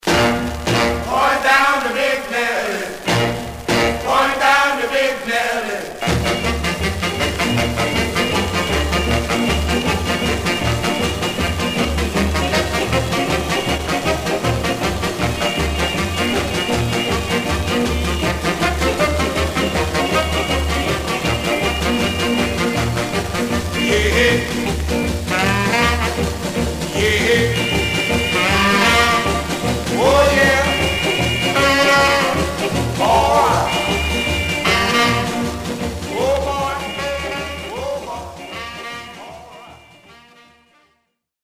Surface noise/wear
Mono
R&B Instrumental